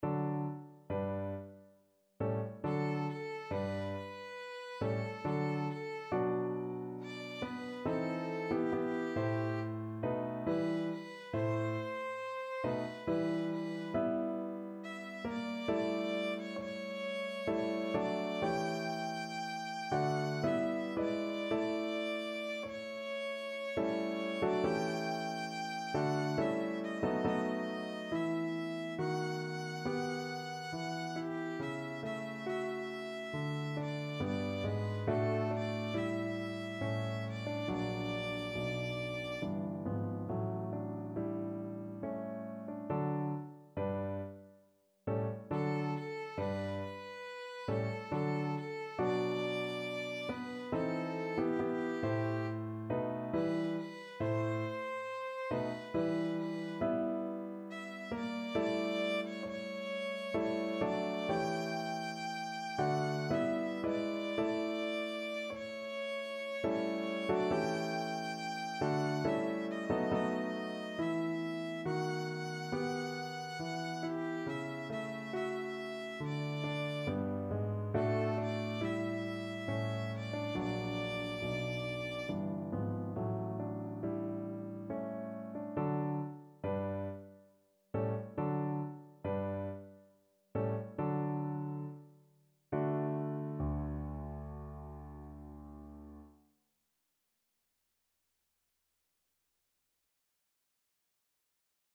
Violin
3/4 (View more 3/4 Music)
Larghetto =69
D major (Sounding Pitch) (View more D major Music for Violin )
Classical (View more Classical Violin Music)